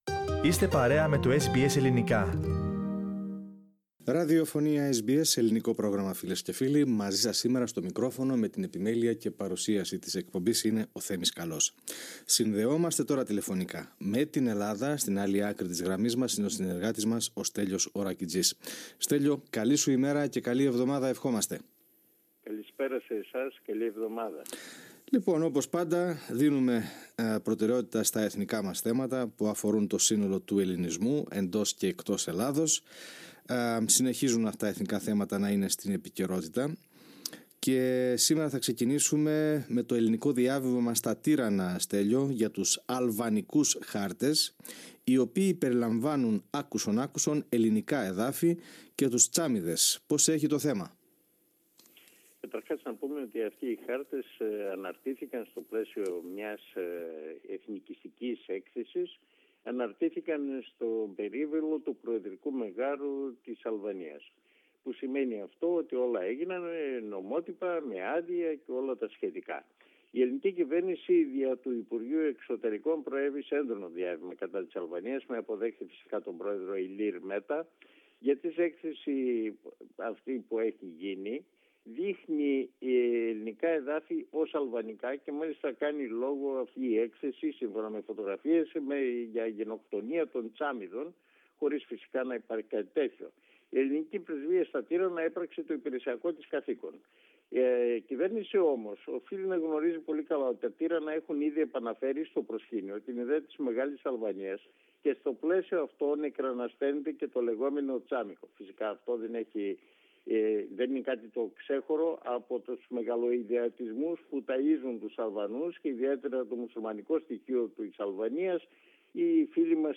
Πατήστε PLAY πάνω στην εικόνα για να ακούσετε την ανταπόκριση μας από την Ελλάδα.